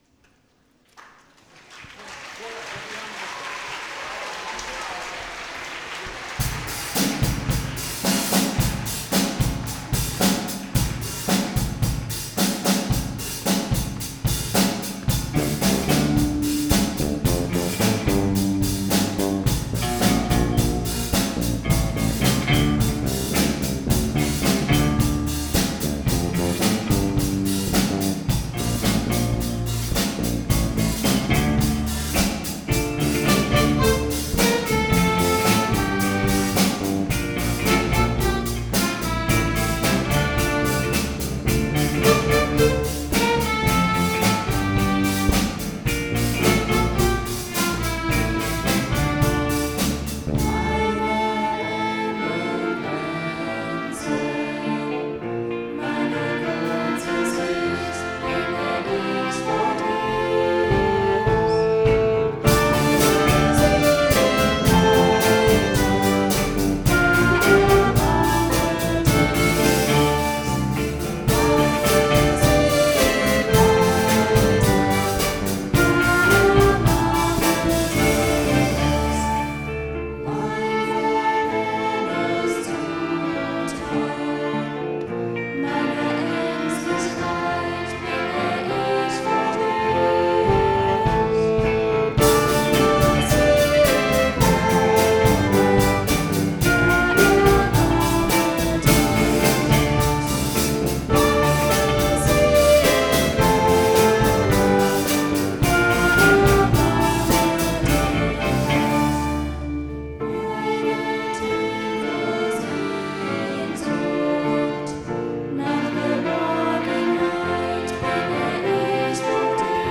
Schülerinnen und Schüler aller Klassenstufen gestalten den musikalischen Part der Gottesdienste und Schulfeiern. Gespielt werden moderne, meist geistliche Lieder und Instrumentaleinlagen.
Diese reicht querbeet von Querflöten, Klarinetten bis Schlagzeug über (E-)Gitarren, Violinen, verschiedene Blasinstrumente, Klavier und Gesang.